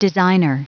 Prononciation du mot designer en anglais (fichier audio)
Prononciation du mot : designer